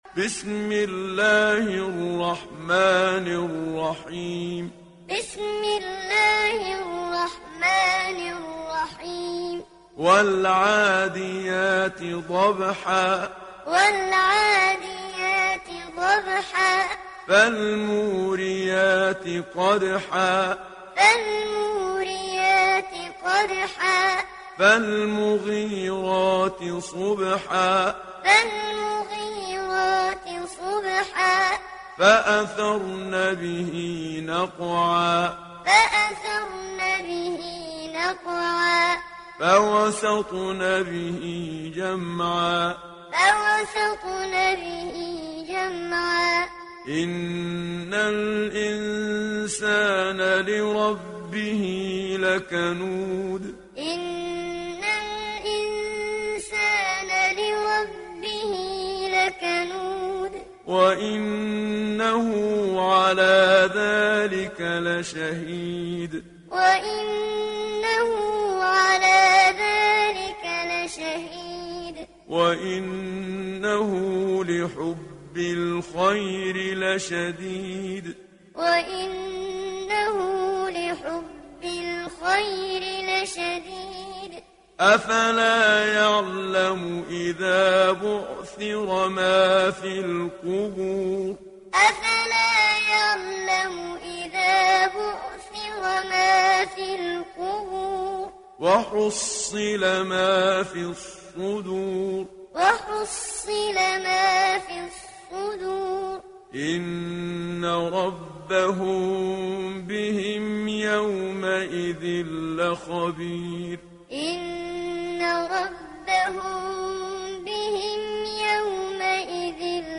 İndir Adiat Suresi Muhammad Siddiq Minshawi Muallim